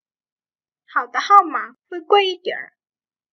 Hǎo de hàomǎ huì guì yīdiǎnr.
Hảo tợ hao mả khuây quây y tẻn.